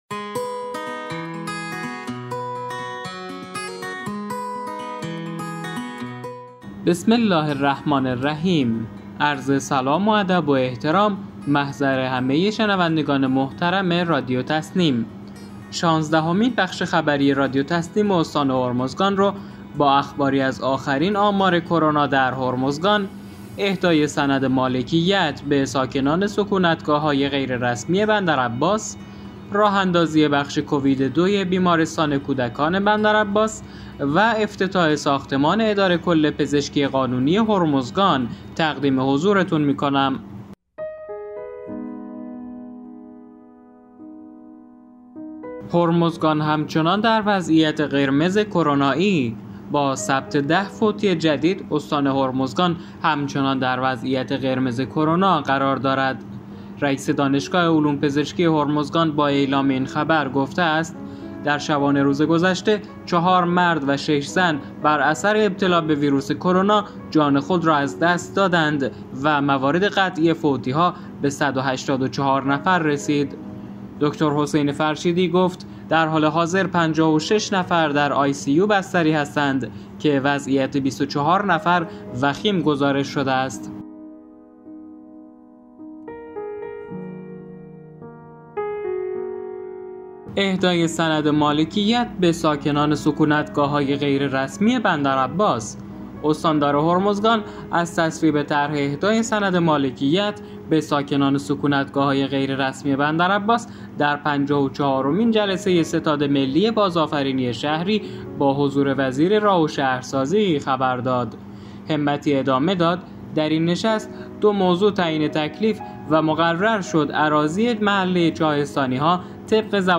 به گزارش خبرگزاری تسنیم از بندرعباس، شانزدهمین بخش خبری رادیو تسنیم استان هرمزگان با اخباری از آخرین آمار کرونا در هرمزگان، اهدای سند مالکیت به ساکنان سکونتگاه‌های غیررسمی بندرعباس، راه اندازی بخش کووید 2 بیمارستان کودکان بندرعباس و افتتاح ساختمان اداره کل پزشکی قانونی هرمزگان منتشر شد.